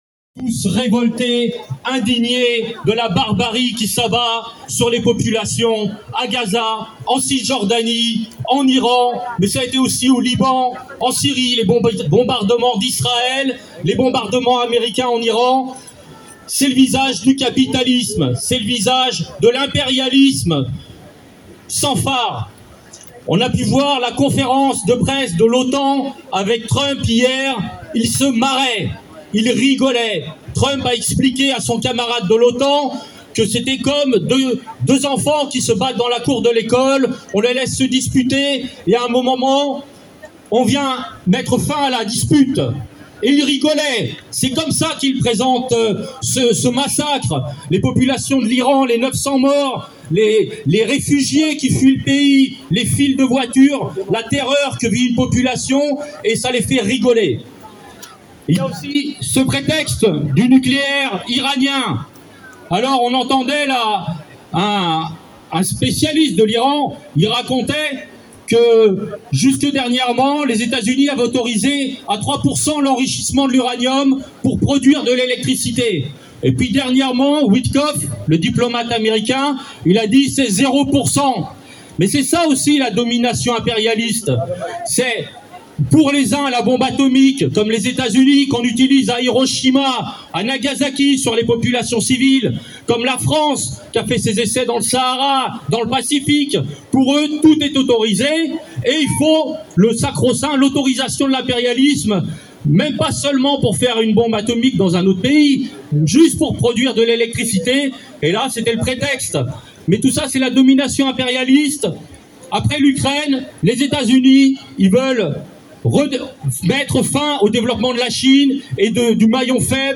Rassemblement contre l’agression impérialiste au Moyen-Orient et dénoncer la poursuite du génocide à Gaza